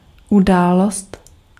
Ääntäminen
IPA: /e.vɛn.mɑ̃/